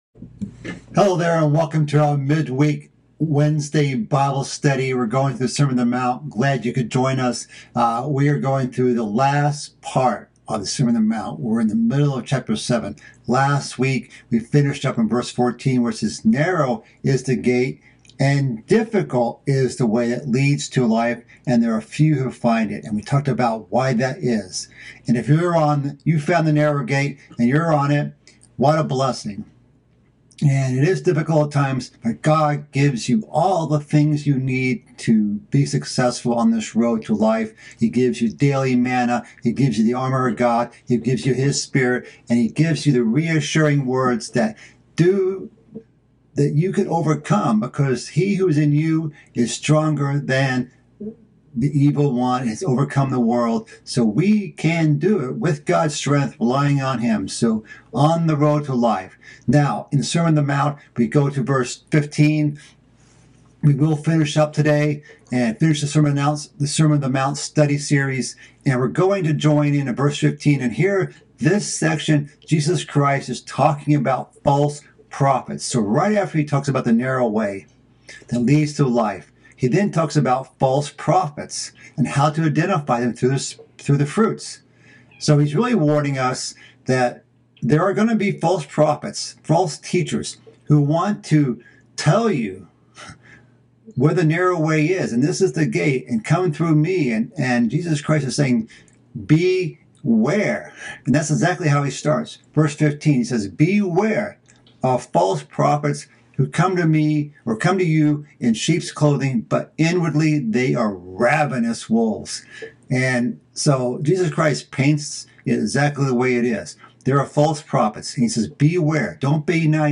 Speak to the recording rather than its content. This is the final part of a mid-week Bible study series about the sermon on the mount. This section covers final topics such as being known by your fruits, and building your house on the rock.